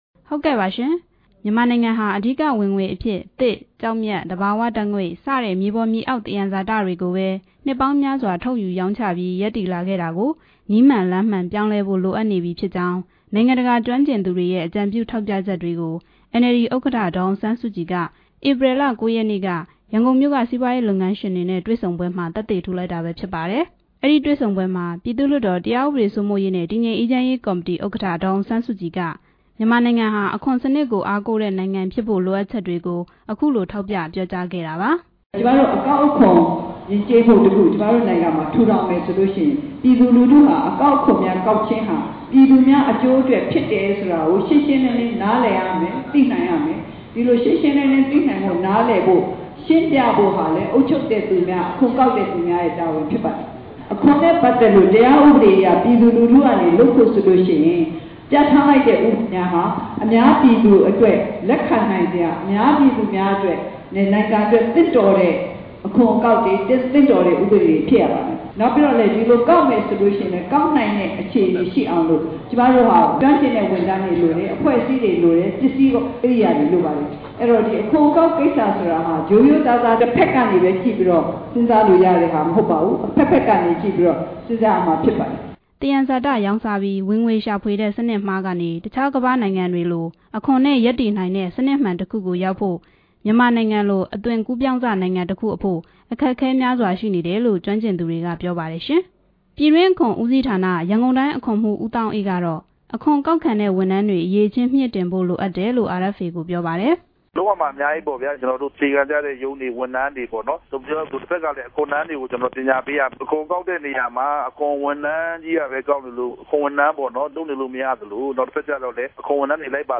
အခွန်ဆောင်တဲ့ကိစ္စနဲ့ ပတ်သက်ပြီး နယ်ပယ်အသီးသီးက ပုဂ္ဂိုလ်တွေနဲ့ မေးမြန်းချက်